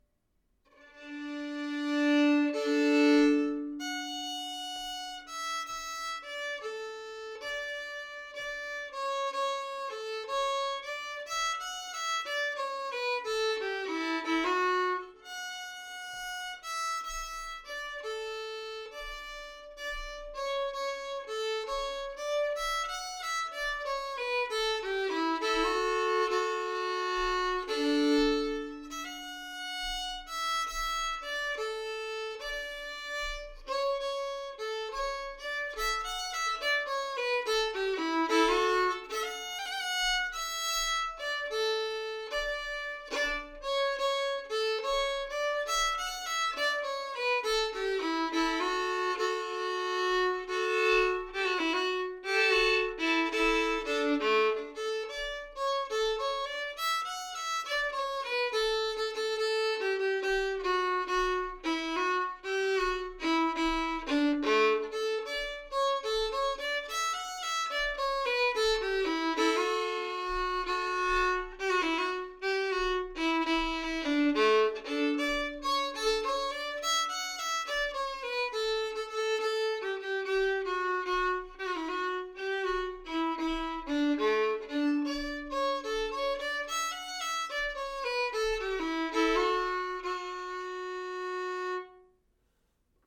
En jämtländsk polska
Kallas A till A för att den börjar med de tonerna, mycket praktiskt. Stämman kan man kalla A till F#.
A-till-A-stämma-slow.mp3